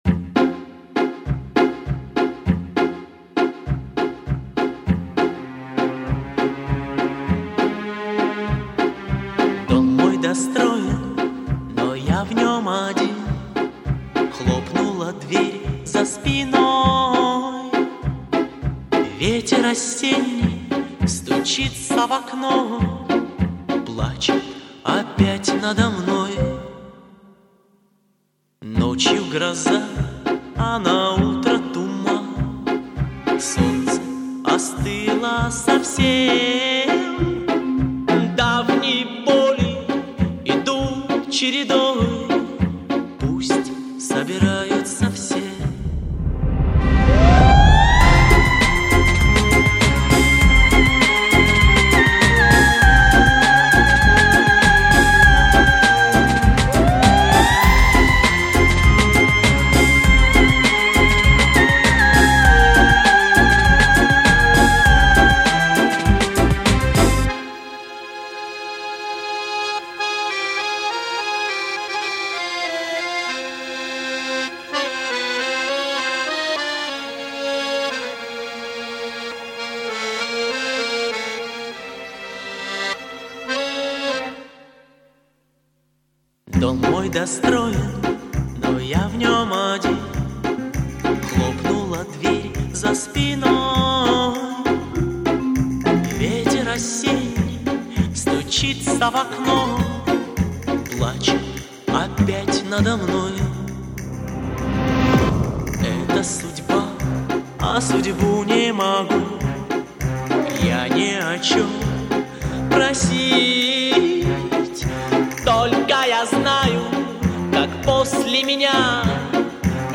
令人震撼的高音区